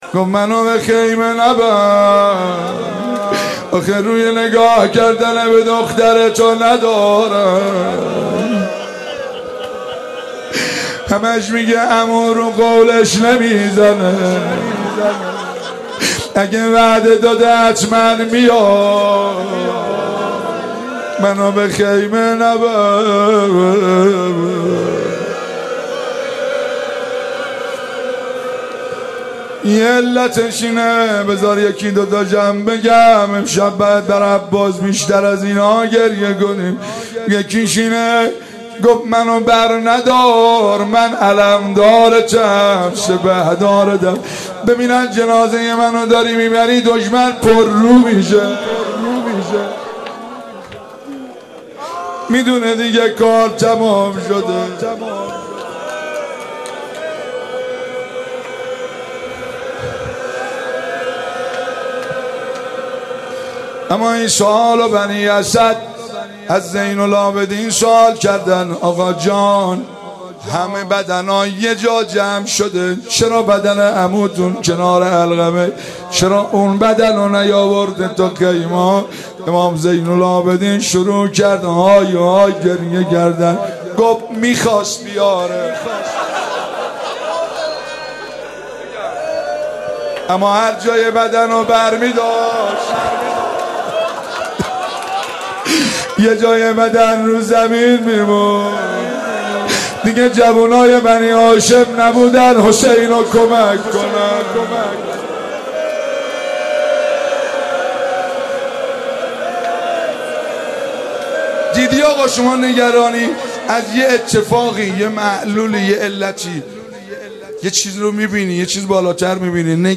شب تاسوعا محرم 95_روضه